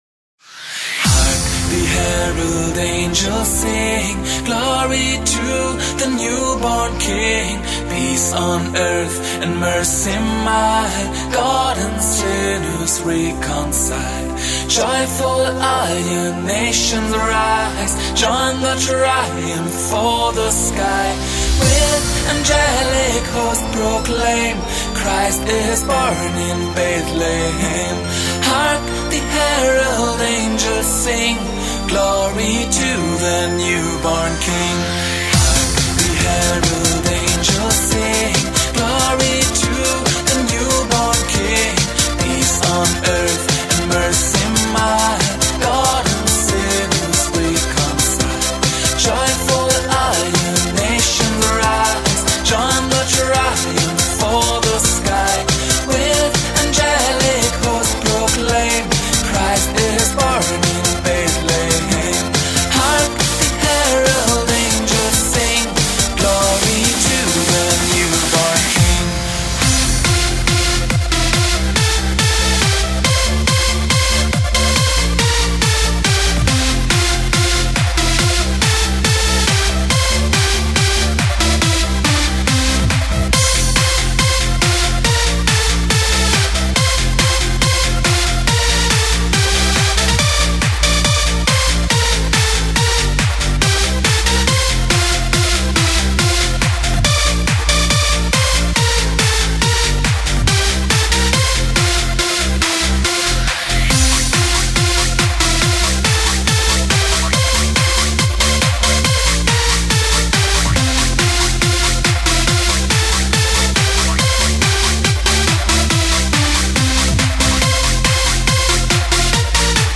Жанр:Super/Club/Dance